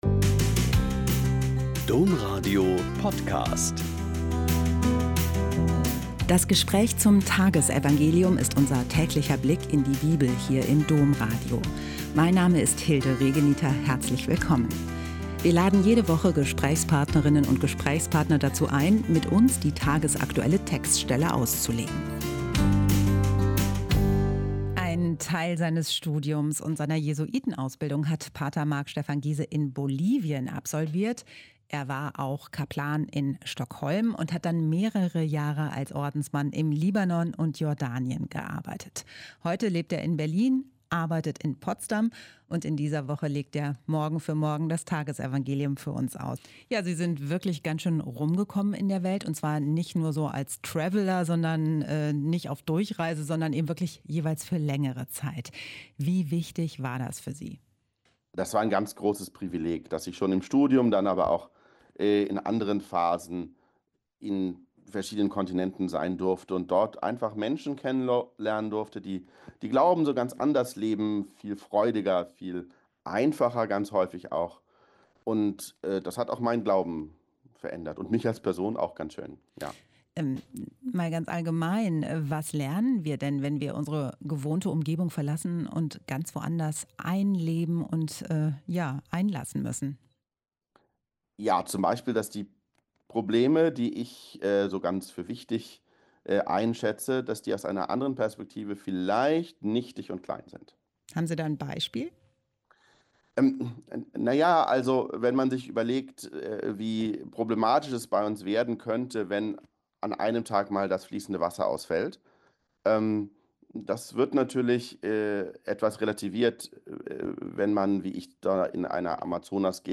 Joh 16,12-15 - Gespräch